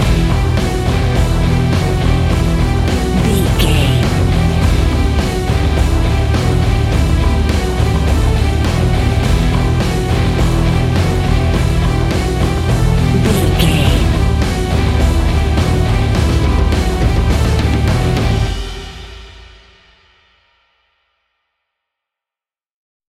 Epic / Action
Fast paced
Aeolian/Minor
hard rock
instrumentals
Heavy Metal Guitars
Metal Drums
Heavy Bass Guitars